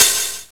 Index of /m8-backup/M8/Samples/Fairlight CMI/IIX/CYMBALS
HHOPEN1.WAV